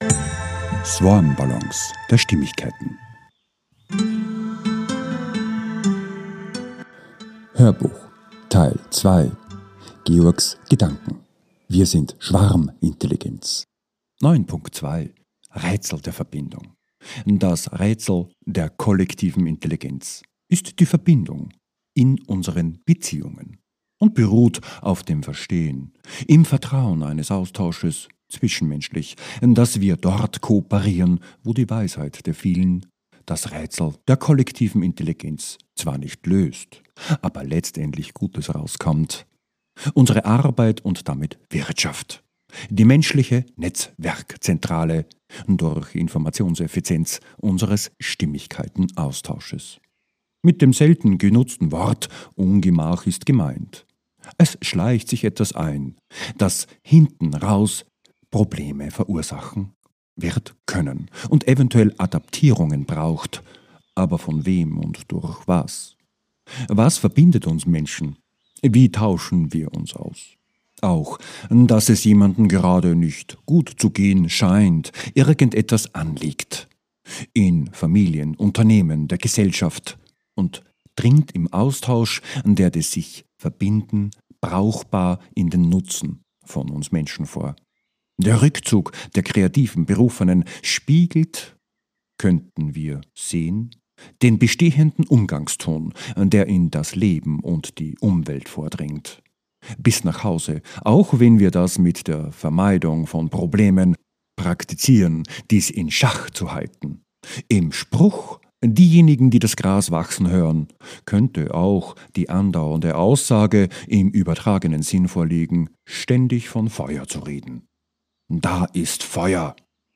HÖRBUCH TEIL 2 - 09.2 - WIR SIND SCHWARMINTELLIGENZ 2 - VERBINDEN - DAS RÄTSEL - 13.02.26, 16.52.m4a ~ SwarmBallons A-Z der Stimmigkeit Podcast